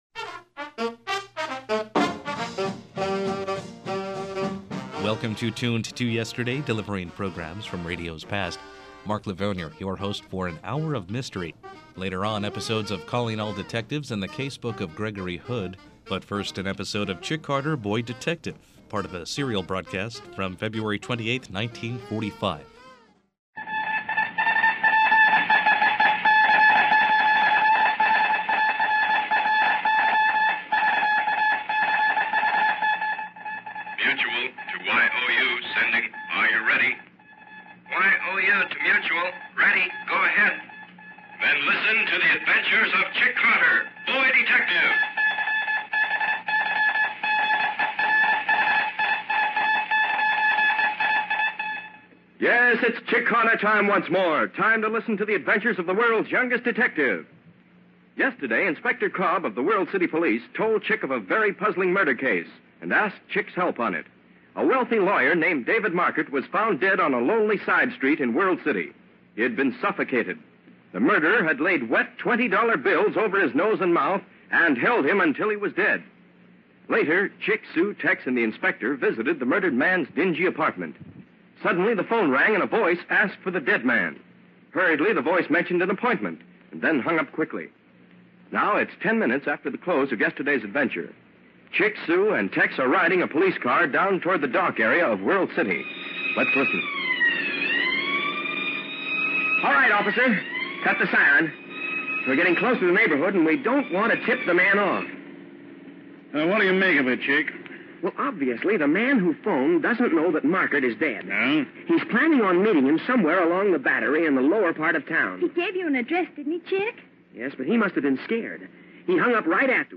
The highest quality broadcasts are restored and played as they were heard years and years ago.
Audio Drama